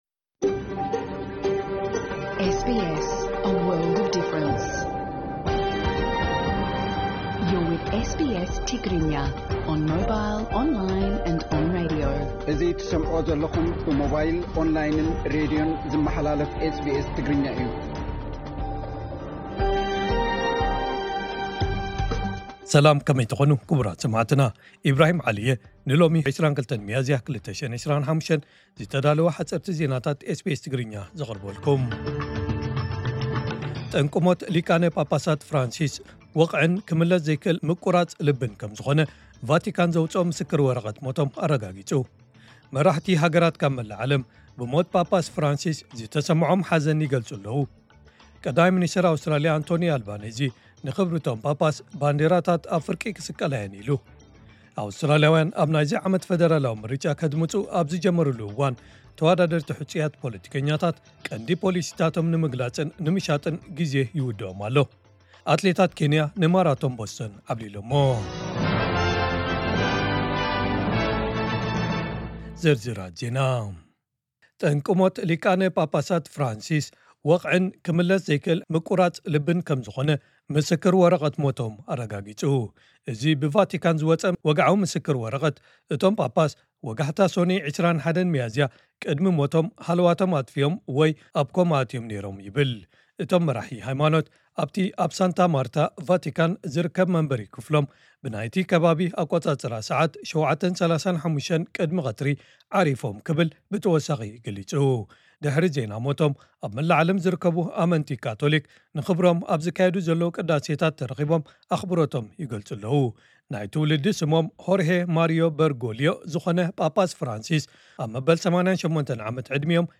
ዕለታዊ ዜና ኤስ ቢ ኤስ ትግርኛ (22 ሚያዝያ 2025)